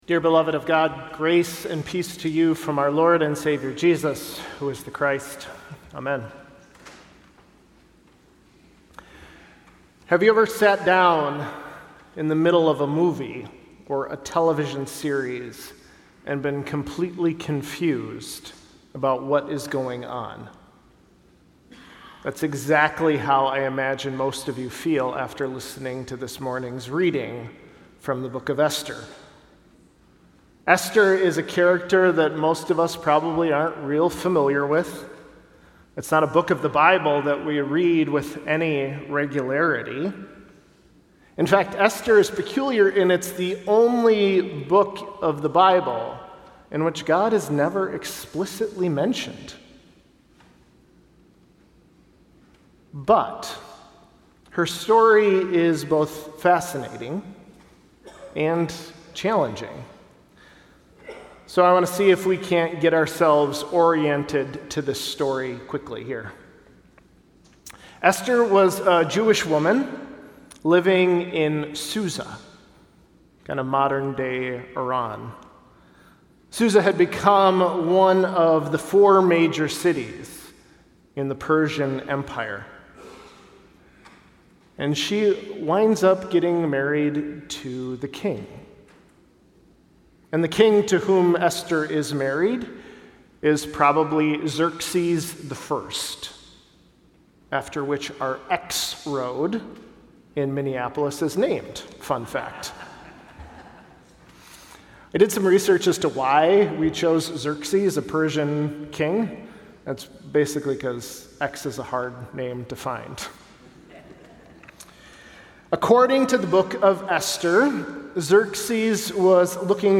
Sermons - Mount Olivet